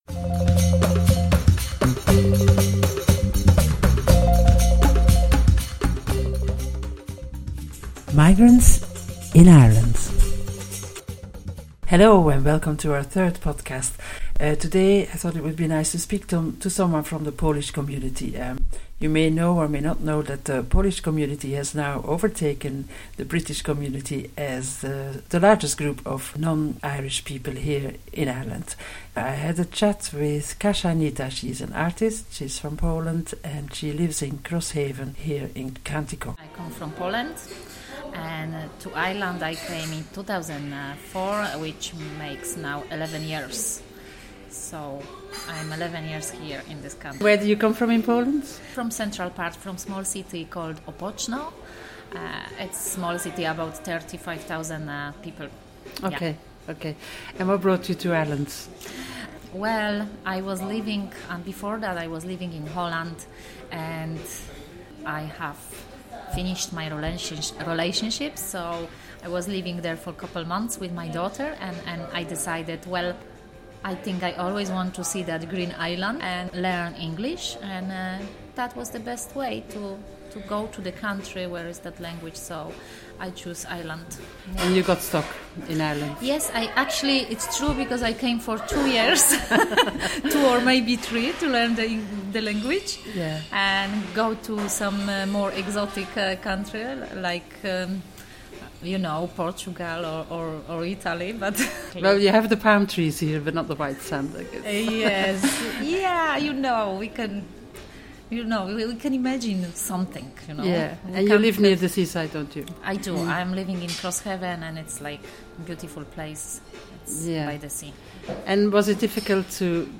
We met in the bar of the Trident hotel in Kinsale and had a chat over a cup of coffee. https